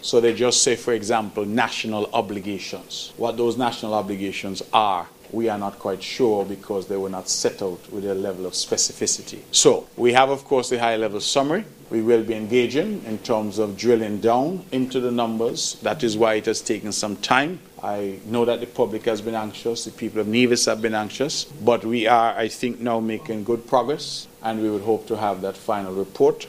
During his press conference for the month of July, Premier Brantley stated that his cabinet had met and deliberated on the much-anticipated report from the World Bank, on how the revenue from the Citizenship by Investment Programme (CBI) should be shared between St. Kitts and Nevis.
Premier Mark Brantley.